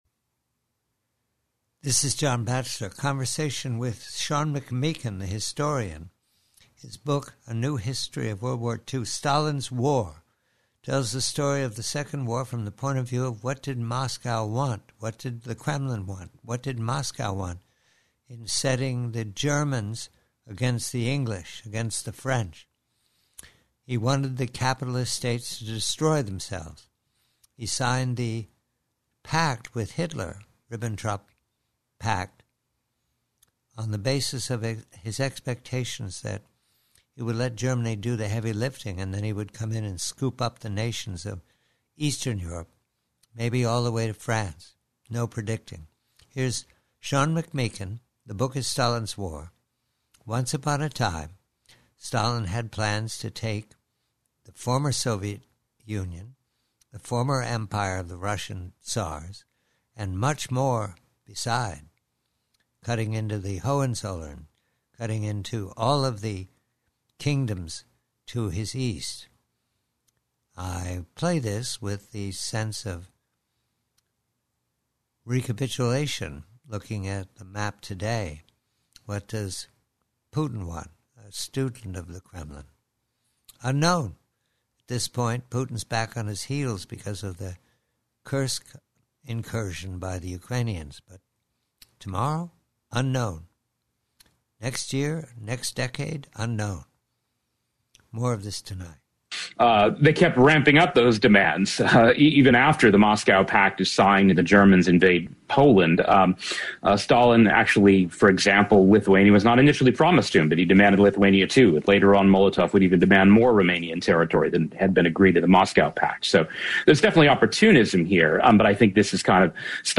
PREVIEW: STALIN'S EMPIRE: Conversation with historian Sean McMeekin, author of STALIN'S WAR, re the Kremlin's war aim from the first was to conquer as much territory as possible while the enemy states Germany and the Allies weakened each other.